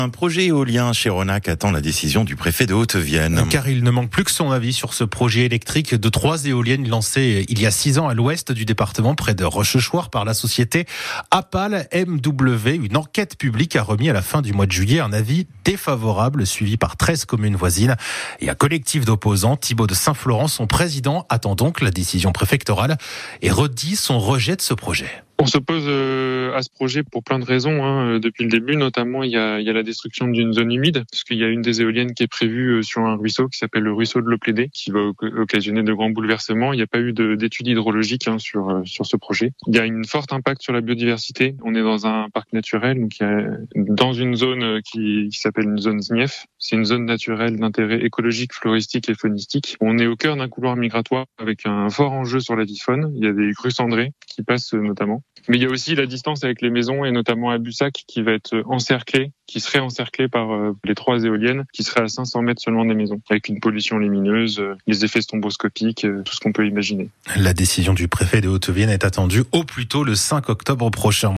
Interview radio Ici Limousin - 13 Aout 2025